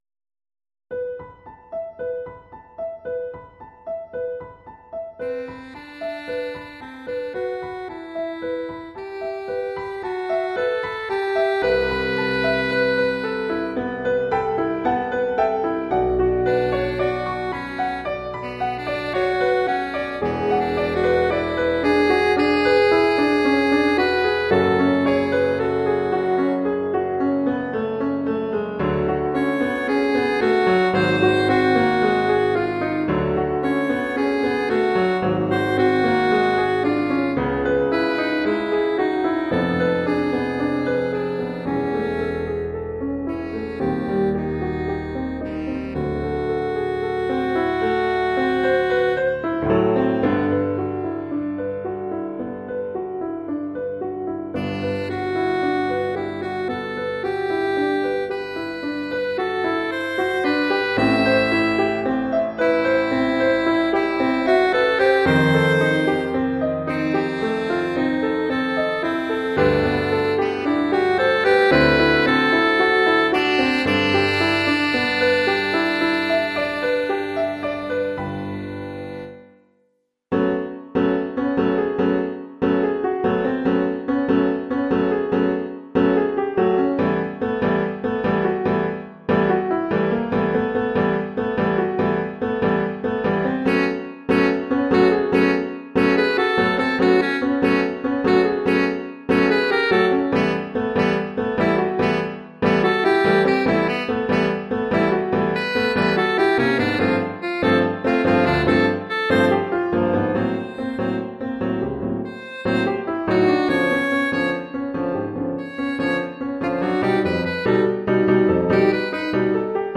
Oeuvre pour saxophone alto et piano.